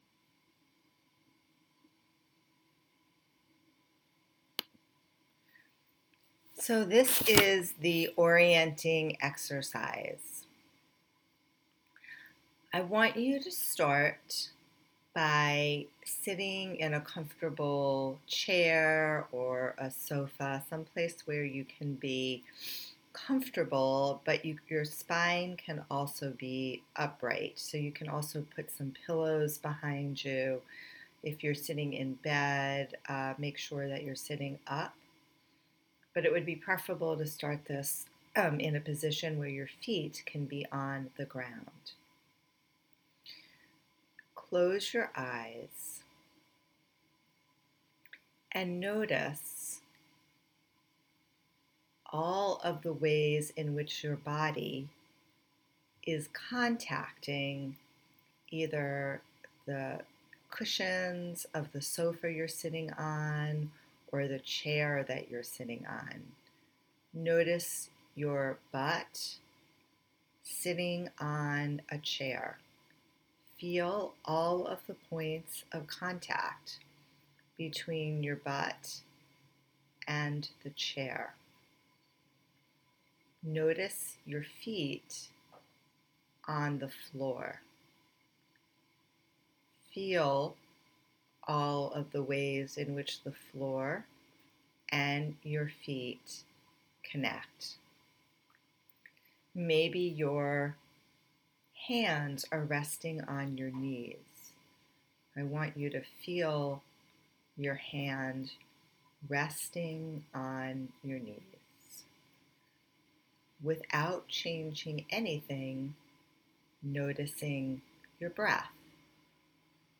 Orientation.meditation.m4a